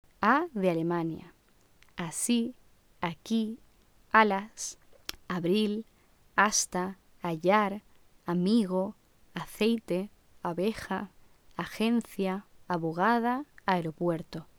[a] de Alemania
La /a/ es una vocal baja (o abierta), central y neutra en cuanto al timbre. En su pronunciación los labios no están redondeados.
Pronunciacion_32_a_.mp3